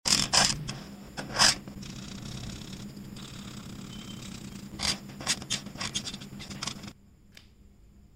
ASMR Colors: Coloring a Wifi sound effects free download
Watch every smooth glide of color and enjoy the relaxing sounds that make ASMR art so addictive.